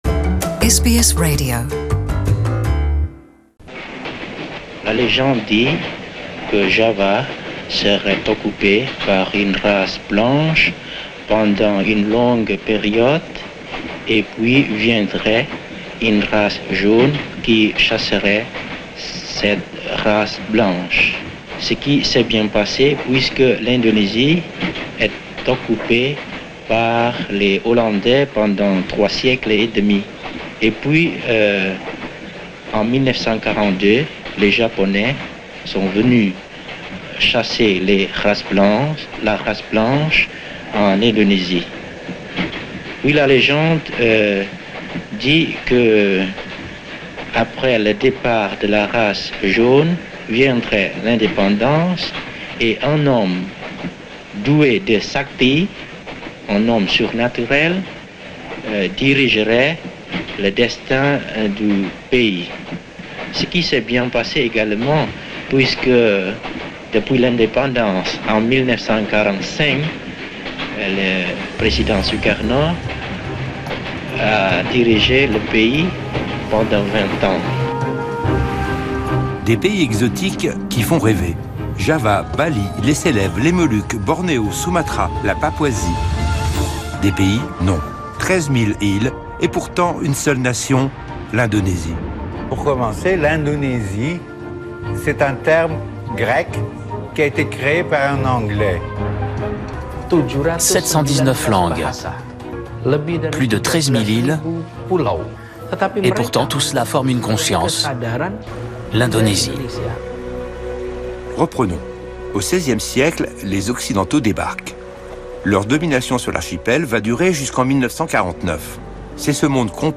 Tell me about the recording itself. Le 17 août 1945, Sukarno proclamait l'indépendance de l'Indonésie après la capitulation des Japonais à la fin de la Seconde Guerre mondiale. Retour sur cet épisode historique avec des extraits combinés de deux films ; le documentaire de Paul Seban et celui de Frédéric Compain.